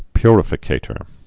(pyrə-fĭ-kātər)